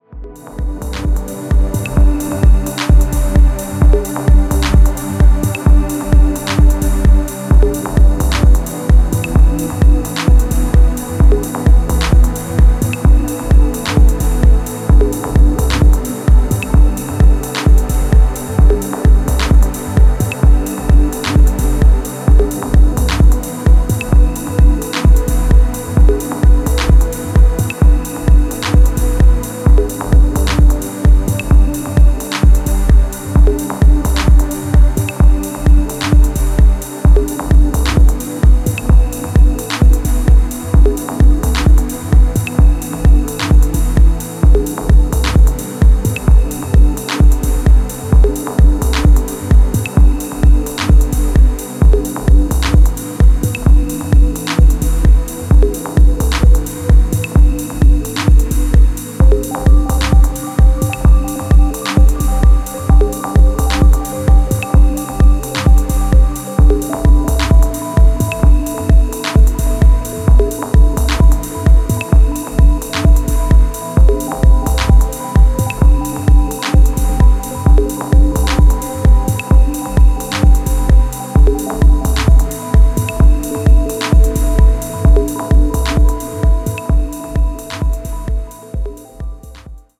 メランコリックなシンセストリングスと瞬くアルペジオが息を飲むほど美しい